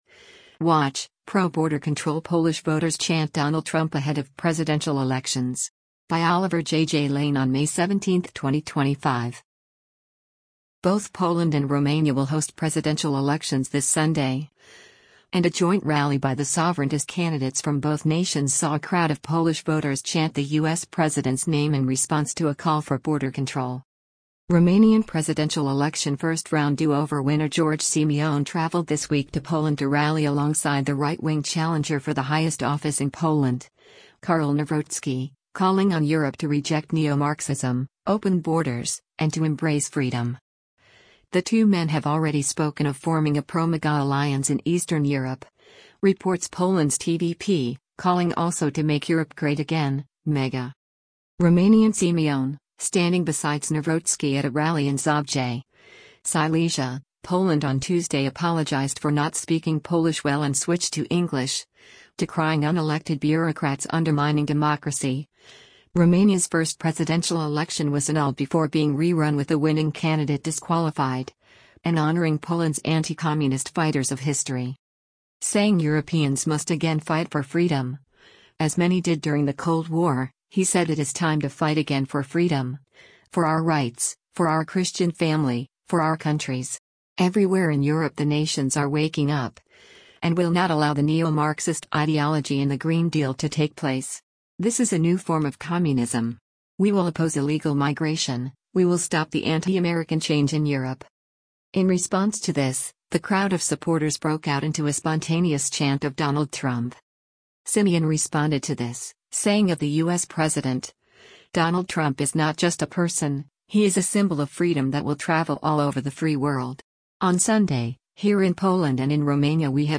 Romanian Simion, standing besides Nawrocki at a rally in Zabrze, Silesia, Poland on Tuesday apologised for not speaking Polish well and switched to English, decrying “unelected bureaucrats” undermining democracy — Romania’s first presidential election was annulled before being re-run with the winning candidate disqualified — and honouring Poland’s “anti-communist fighters” of history.
In response to this, the crowd of supporters broke out into a spontaneous chant of “Donald Trump”.